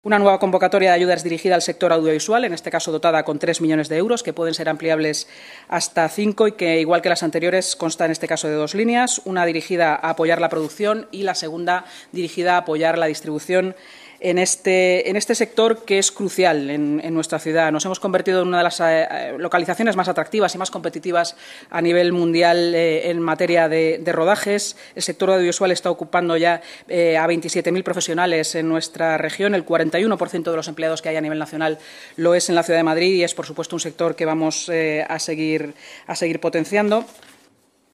Nueva ventana:Inma Sanz, vicealcaldesa y portavoz municipal